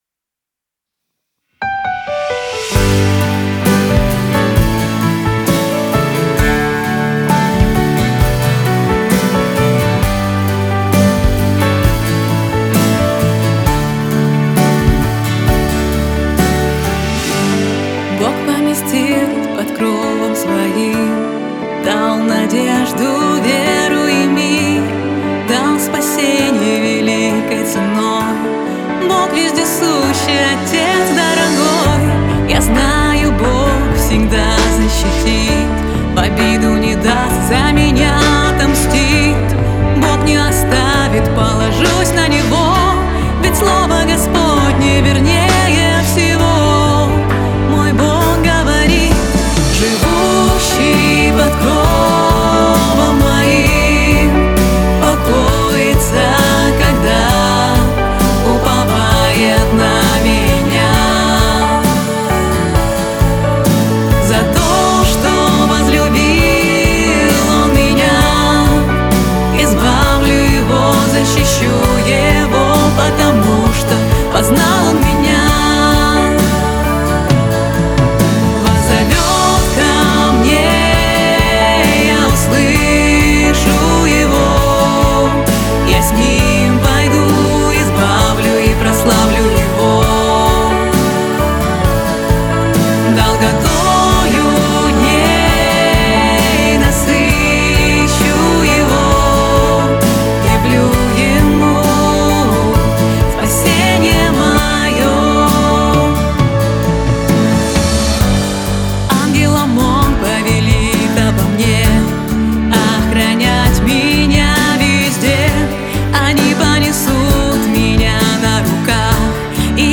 Категория: Поклонение